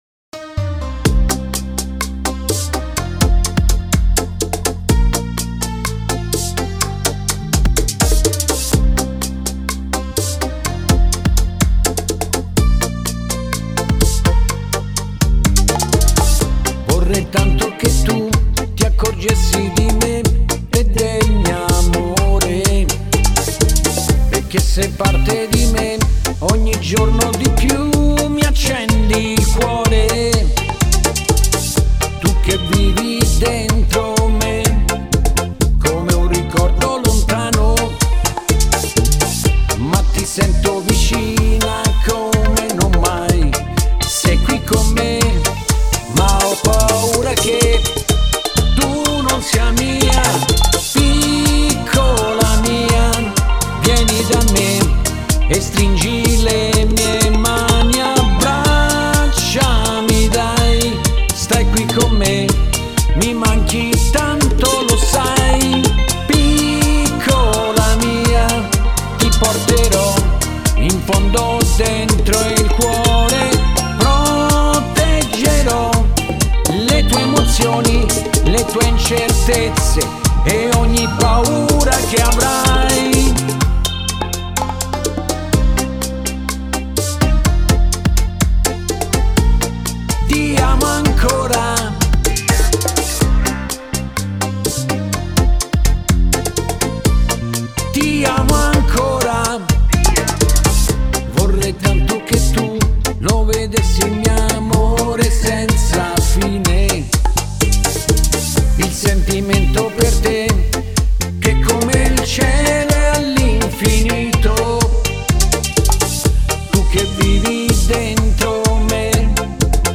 Bachata pop
Bachata